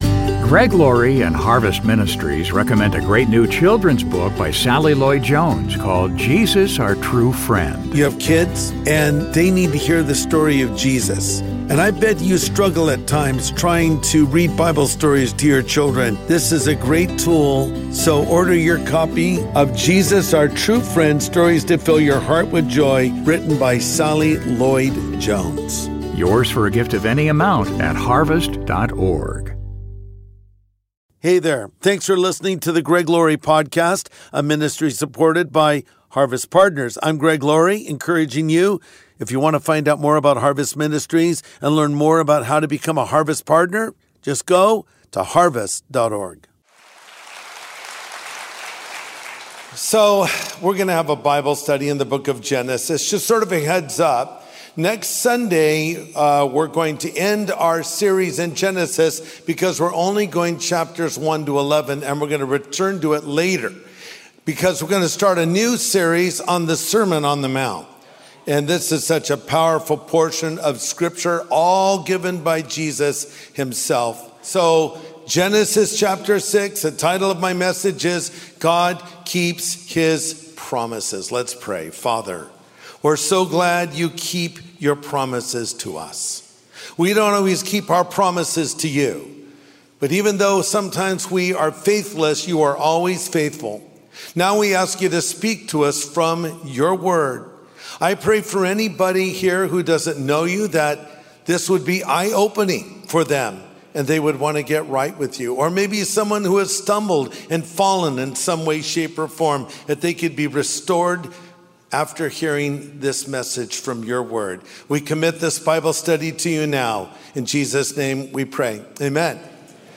God Keeps His Promises | Sunday Message